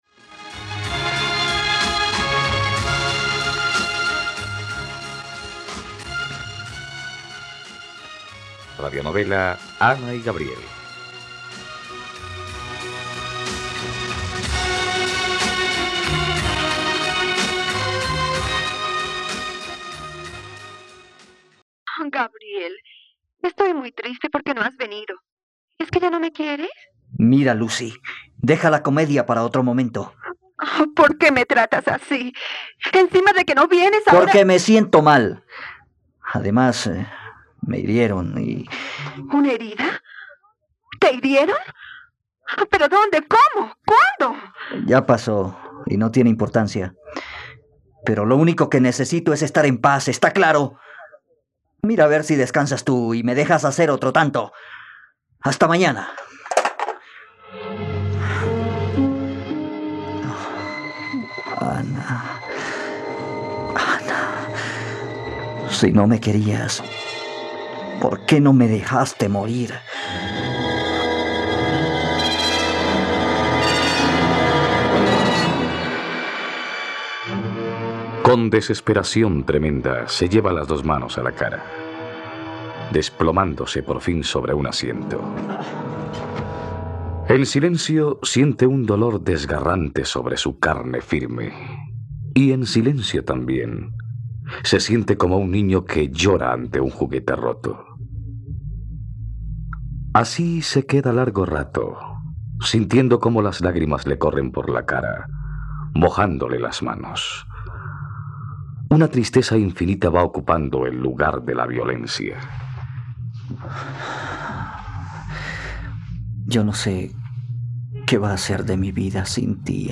..Radionovela. Escucha ahora el capítulo 64 de la historia de amor de Ana y Gabriel en la plataforma de streaming de los colombianos: RTVCPlay.